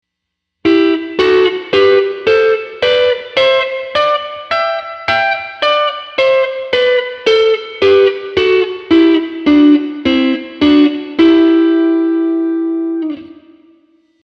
3度音程のダブルストップ2弦、3弦